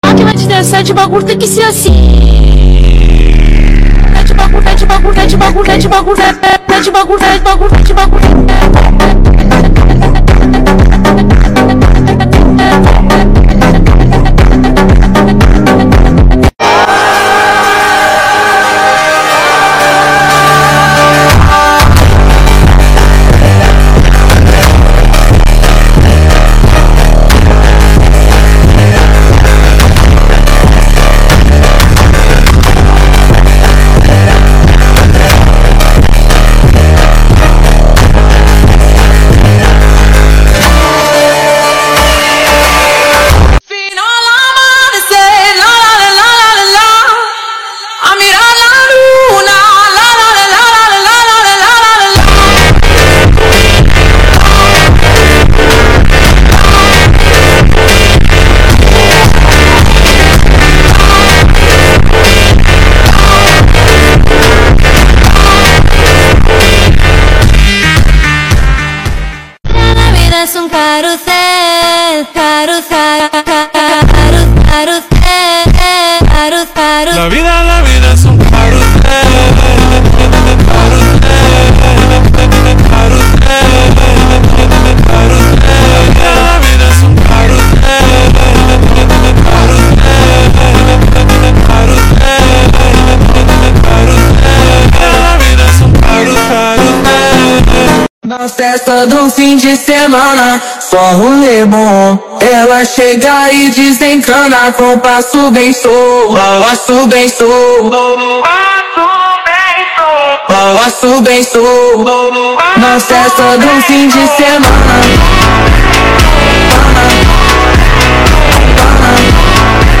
My Top 5 Favorite Phonk Sound Effects Free Download